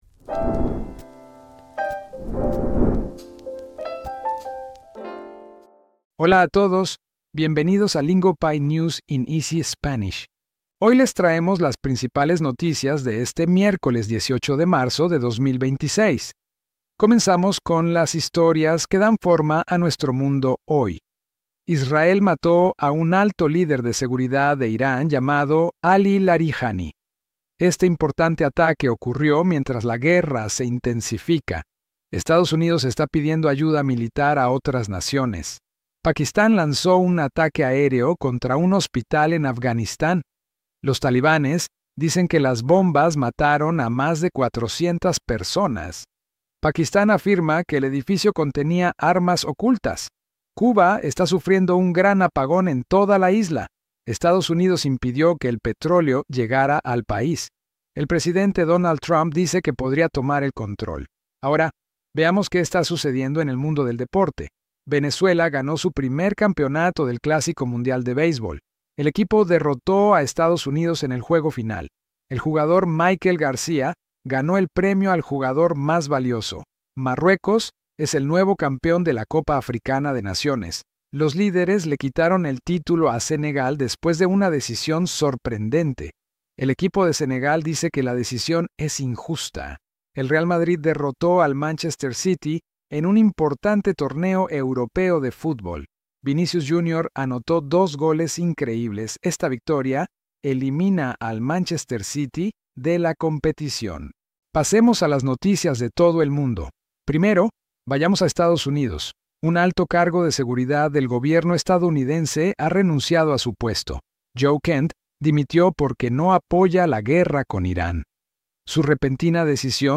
We keep the Spanish clear and beginner-friendly, so you can follow along without getting lost.